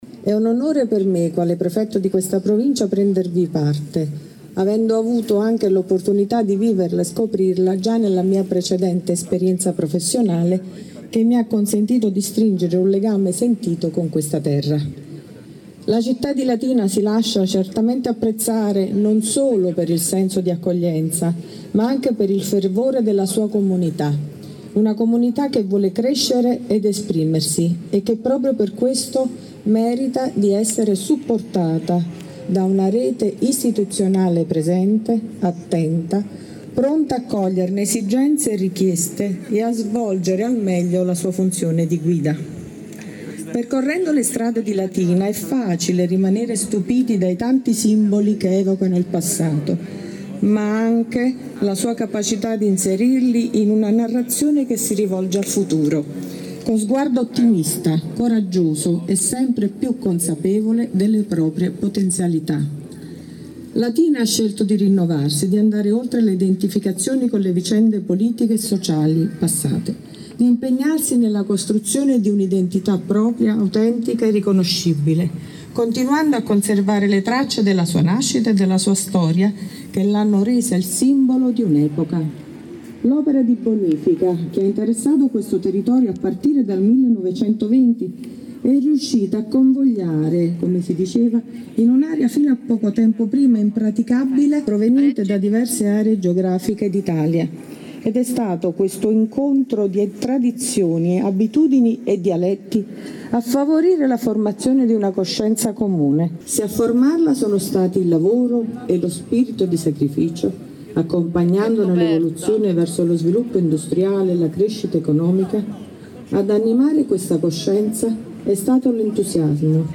In chiusura della cerimonia al Bonificatore, il saluto del Prefetto, Vittoria Ciaramella. Ascoltiamo un estratto del suo intervento.